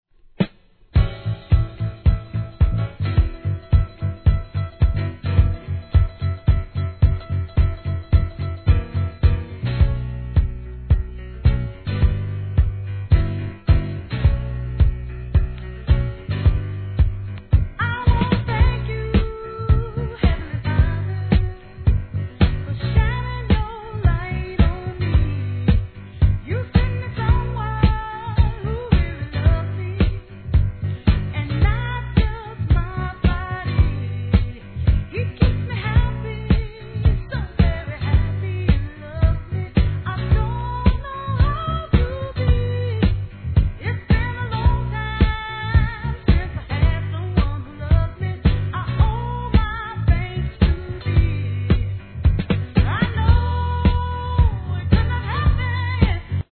¥ 1,650 税込 関連カテゴリ SOUL/FUNK/etc...